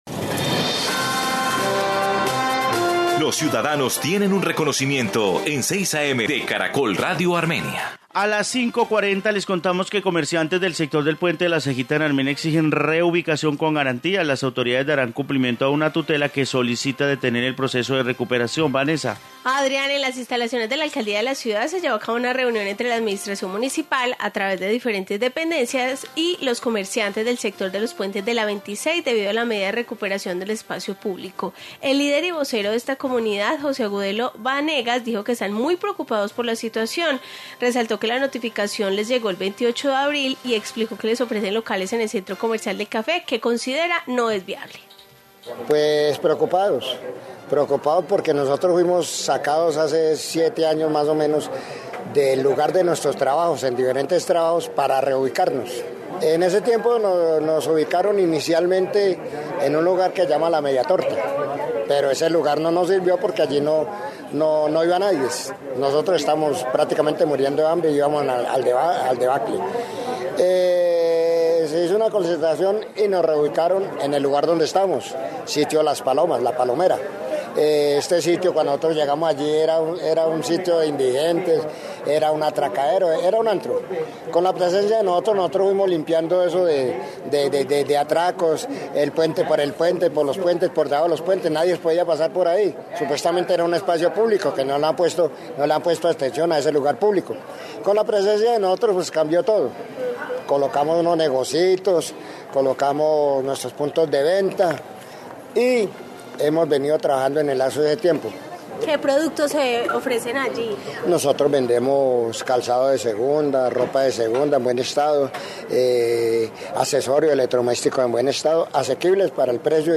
Informe sobre recuperación del puente de la 26 de Armenia